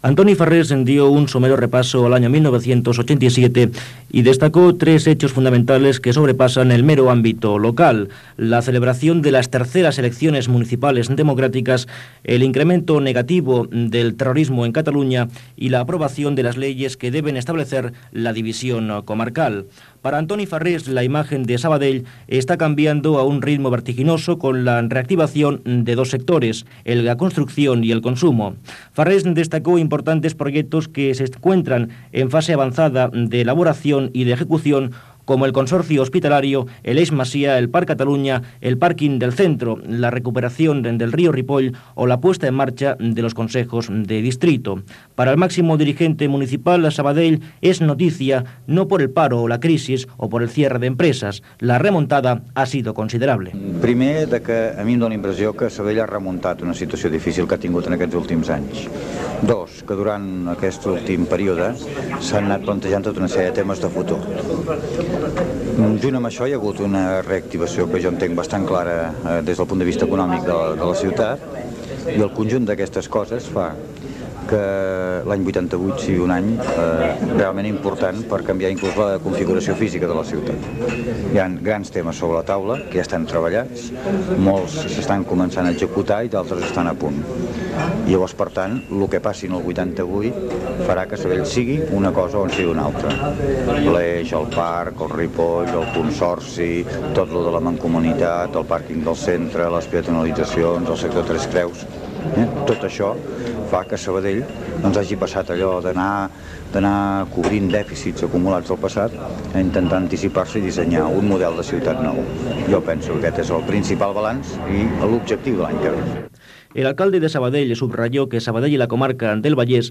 Declaracions de l'alcalde de Sabadell, Antoni Farrés, just a punt d'acabar l'any 1987
Informatiu